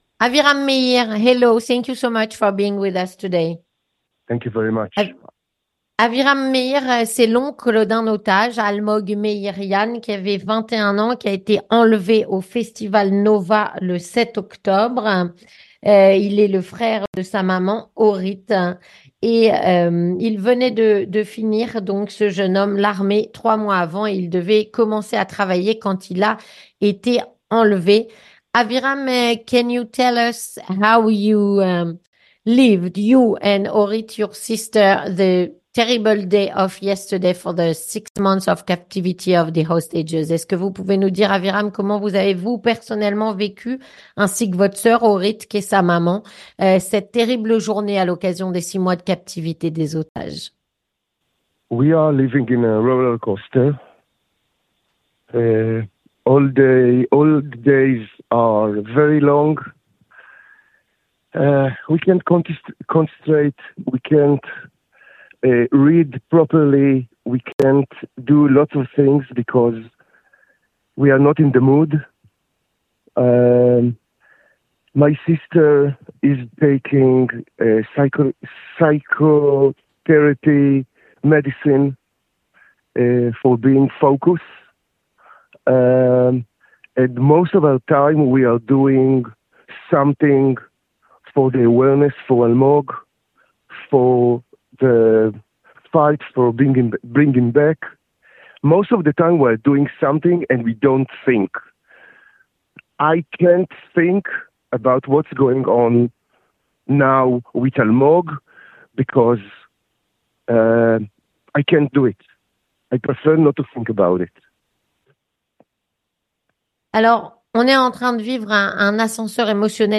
Témoignage - 6 mois de captivité pour les otages : les familles sont à bout de force.